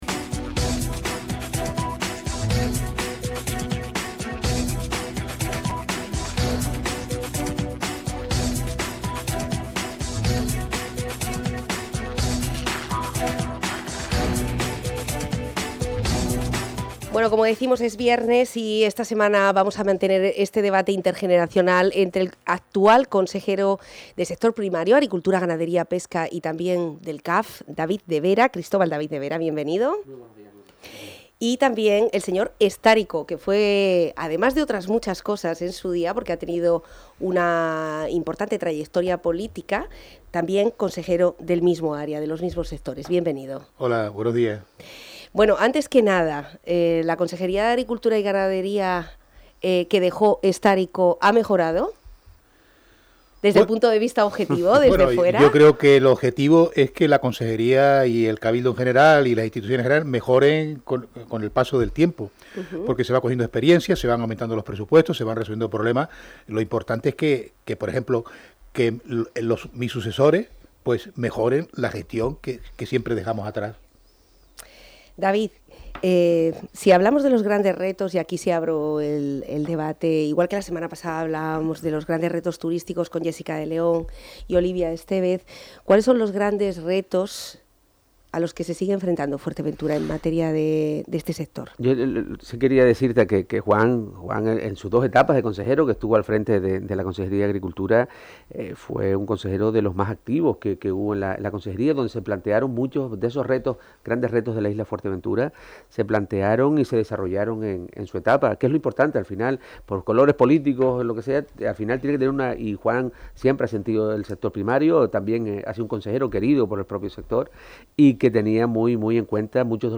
Con el convencimiento por parte de ambos de la necesidad de dar continuidad a los proyectos y luchar por el sector, la entrevista completa se puede escuchar aquí: